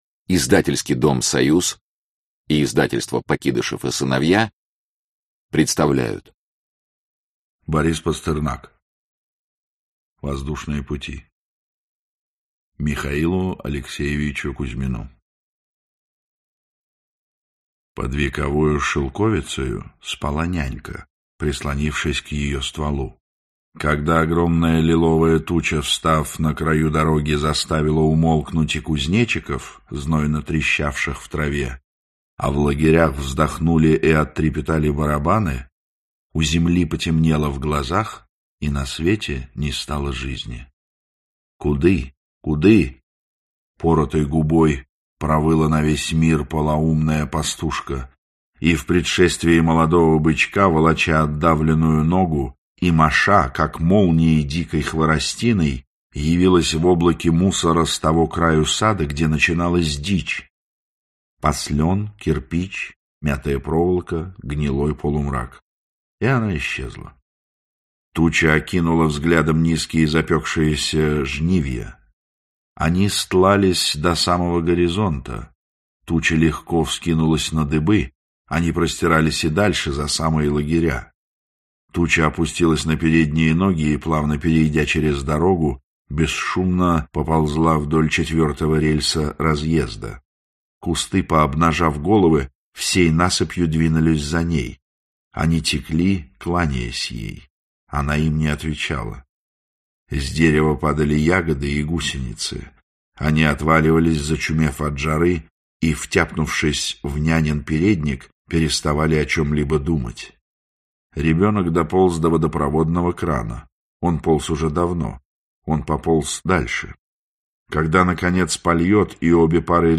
Аудиокнига Воздушные пути | Библиотека аудиокниг